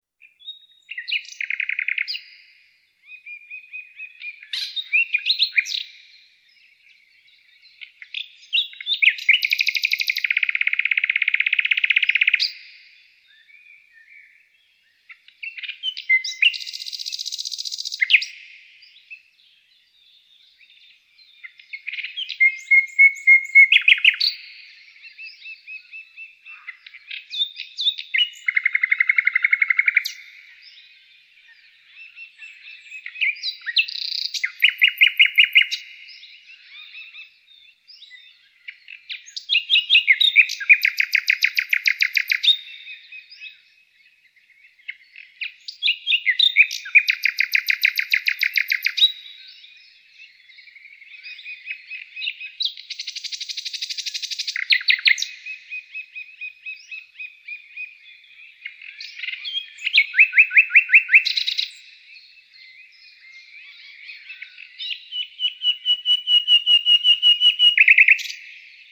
Природа - Звуки лета (summer)
Отличного качества, без посторонних шумов.
995_zvuki-leta.mp3